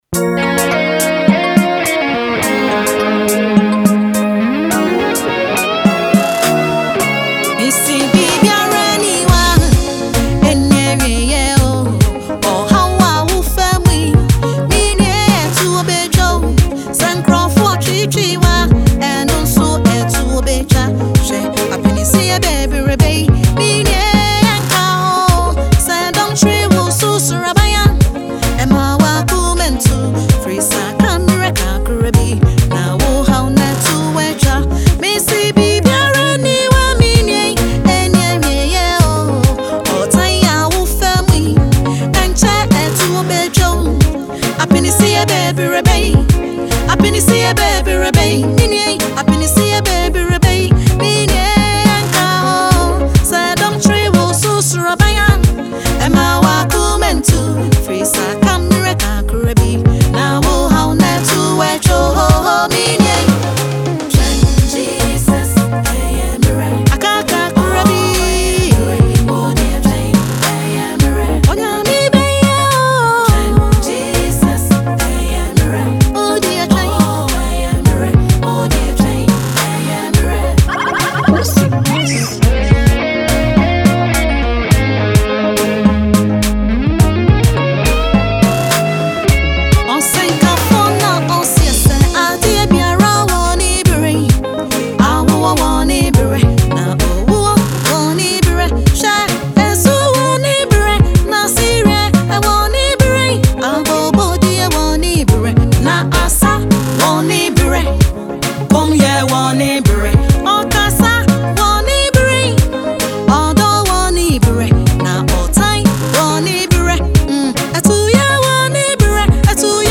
Ghanaian gospel musician
gospel single